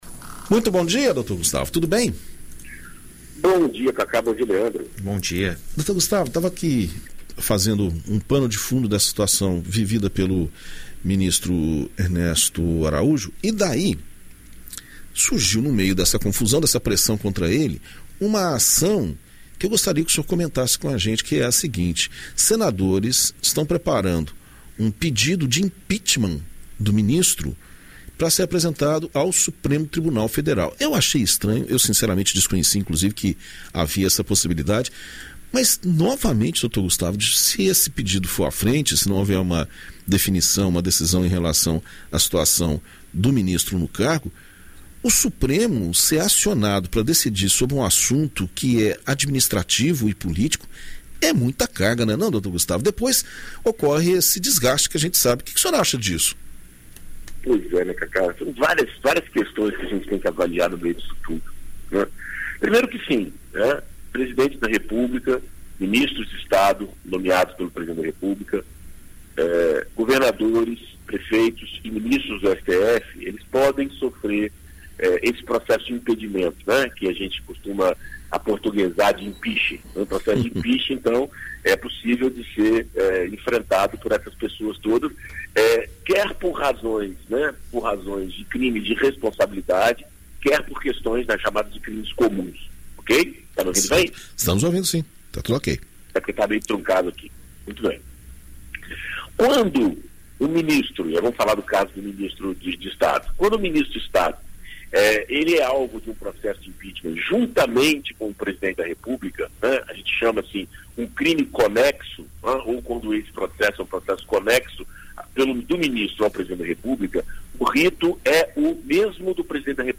BandNews FM Espírito Santo, o advogado e mestre em Direito Constitucional